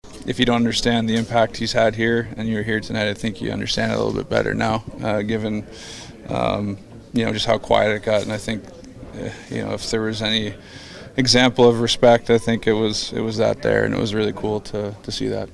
As the Penguins played a video tribute to Crosby after the historic achievement, the PPG Paints Arena crowd suddenly fell silent when Lemieux’s image appeared with his congratulations. Crosby says that was a special moment.